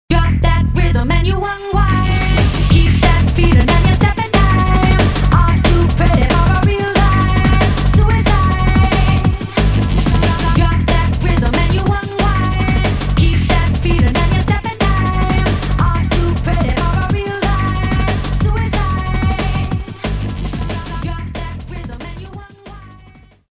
• Качество: 320, Stereo
быстрые
drum n bass
Лютый Драм эн Бейс!